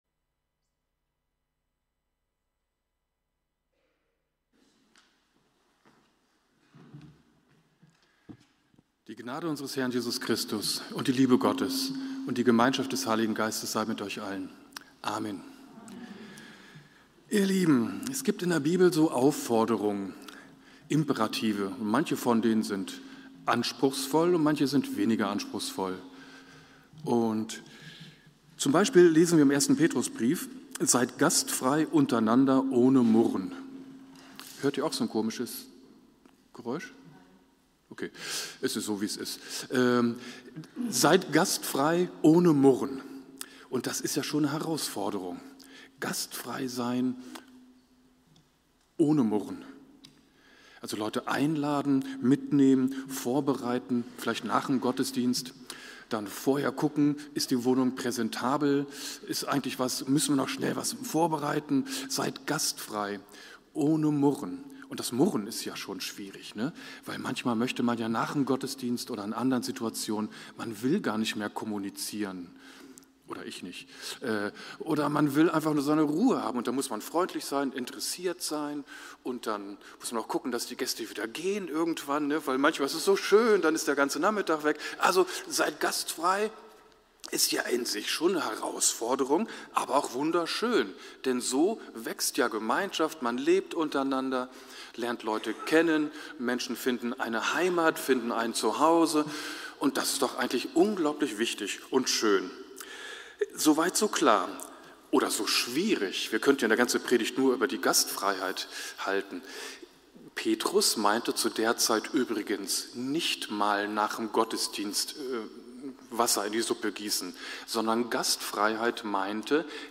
Predigt-26.10-online-audio-converter.com_.mp3